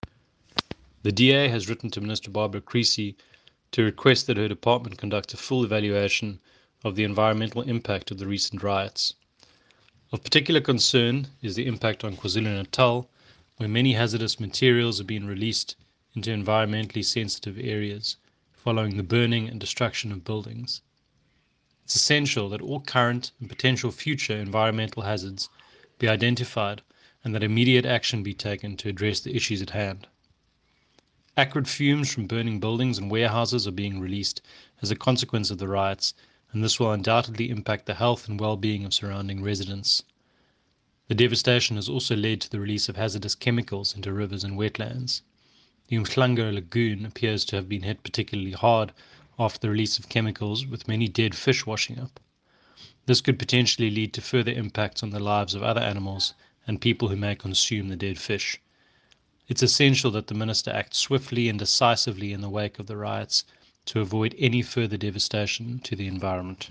soundbite by Dave Bryant MP.